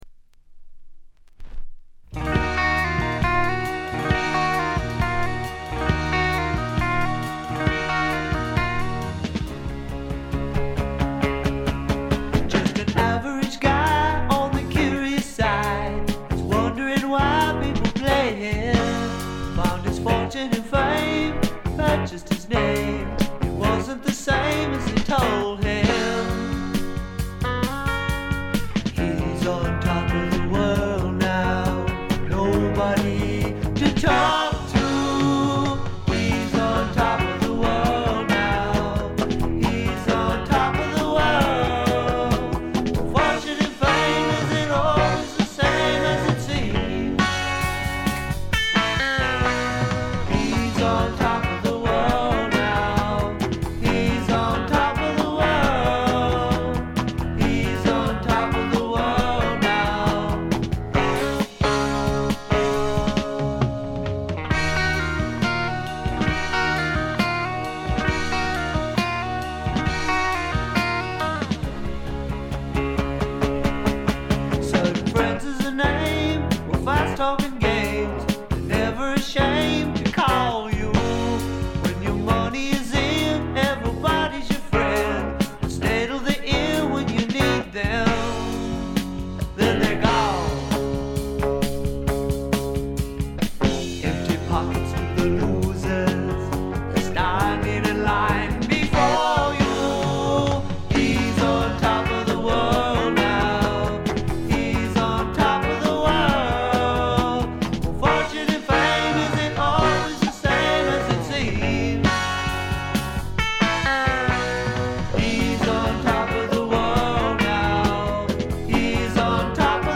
ごくわずかなノイズ感のみ。
シンプルなギター・ポップと言ったおもむきでばっちりハマる人もいそうなサウンドです。
試聴曲は現品からの取り込み音源です。
Lead Guitar, Rhythm Guitar, Vocals
Bass, Vocals
Drums